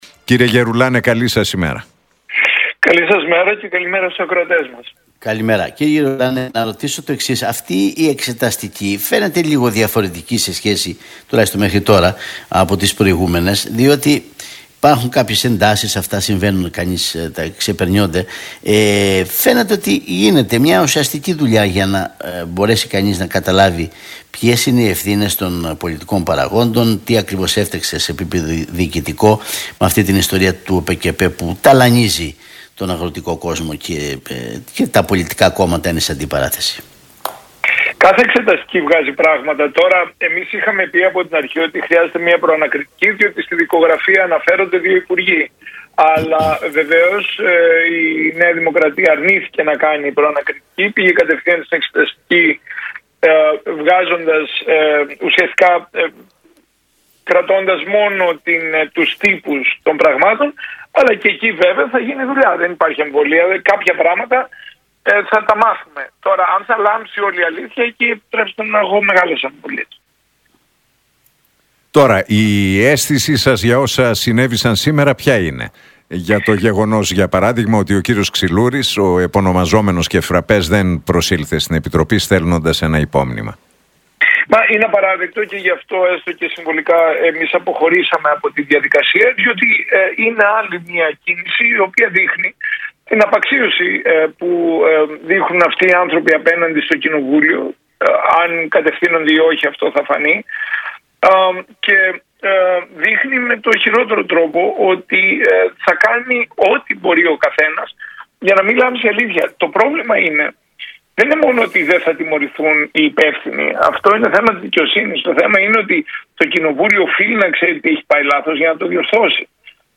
Γερουλάνος στον Realfm 97,8: Το ΠΑΣΟΚ πρέπει να κάνει την ανατροπή - Δουλειά μας είναι να μιλήσουμε στους ψηφοφόρους μας που δεν πάνε στην κάλπη